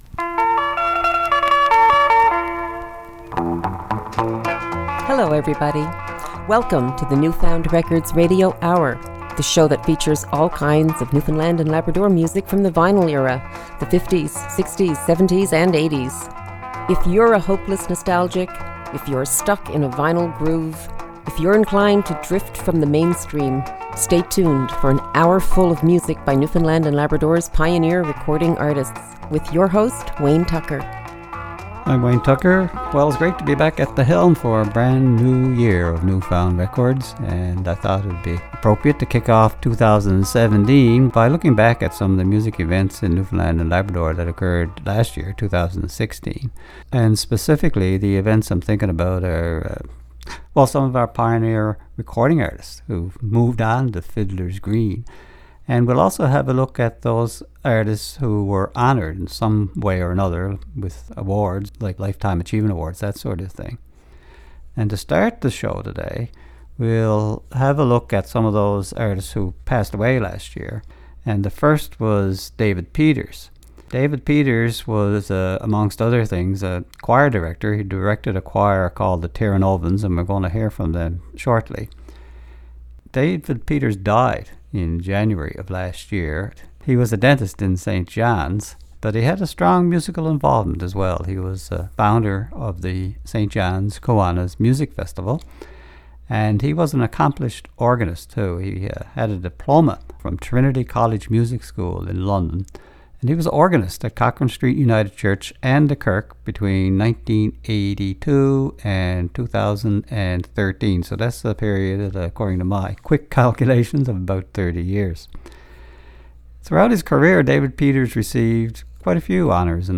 Recorded at CHMR studios, Memorial University, St. John's, NL.